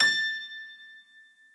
admin-fishpot/b_piano1_v100l8o7a.ogg